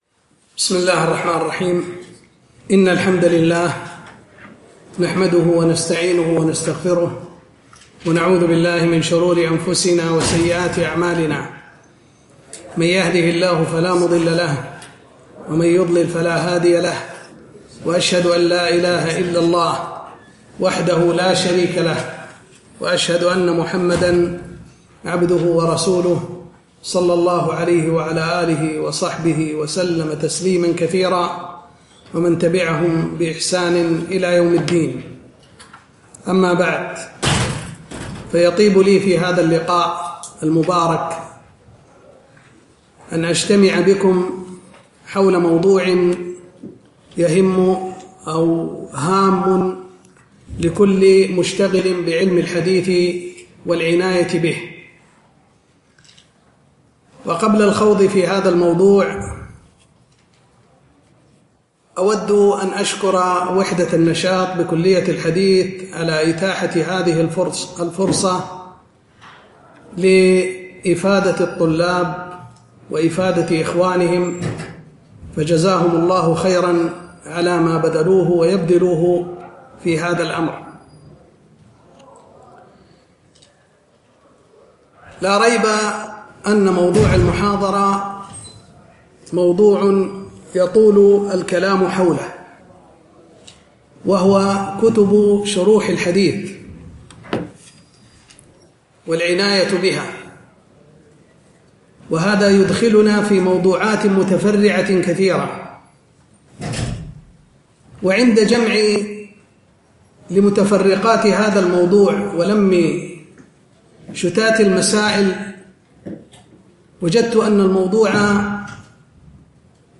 محاضرة - كتب شروح الحديث أنواعها-خصائصها-كيفية الاستفادة منها